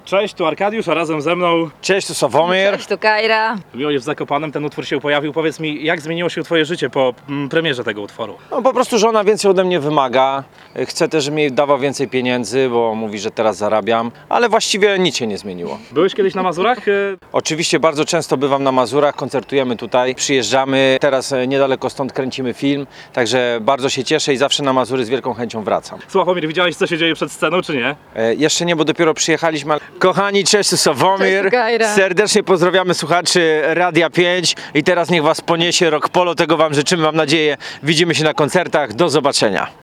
Mobilne studio Radia 5 cieszyło się ogromnym zainteresowaniem uczestników imprezy. Jeszcze przed koncertem zawitał do nas również Sławomir.